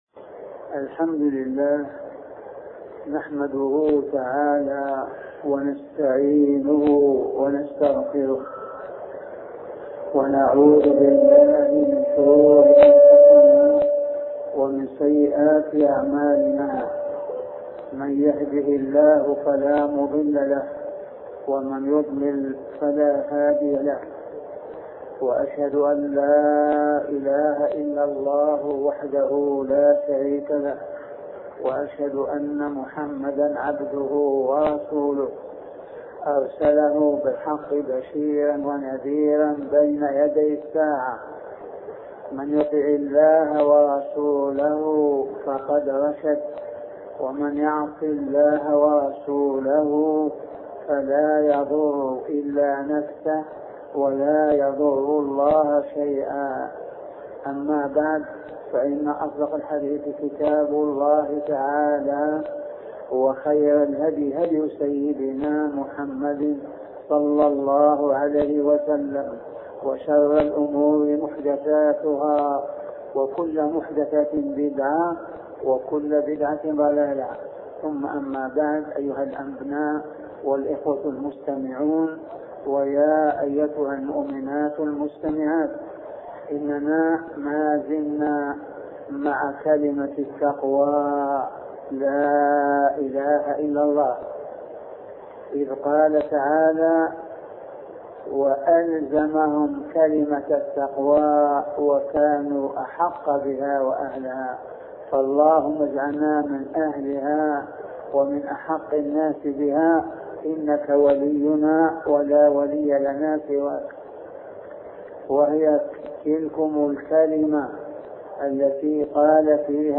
سلسلة محاطرات بعنوان معنى لا إله إلا الله